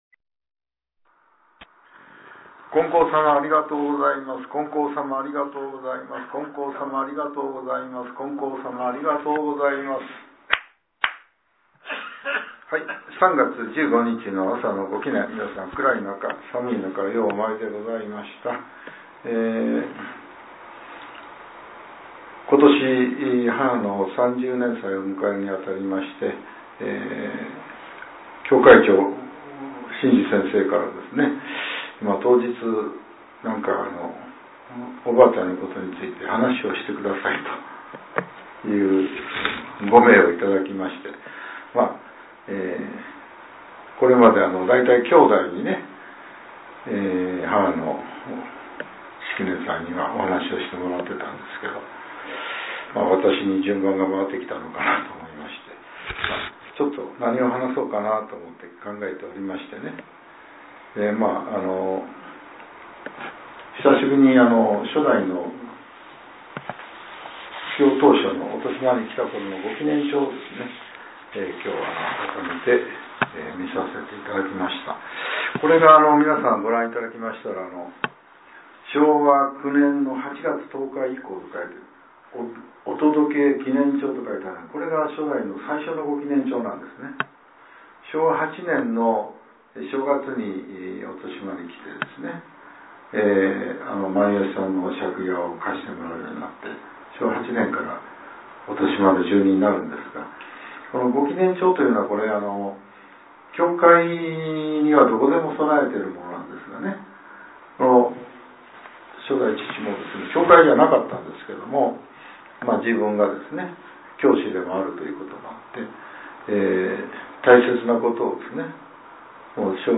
令和８年３月１５日（朝）のお話が、音声ブログとして更新させれています。